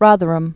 (rŏthər-əm)